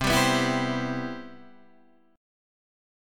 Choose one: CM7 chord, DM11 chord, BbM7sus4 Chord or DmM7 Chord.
CM7 chord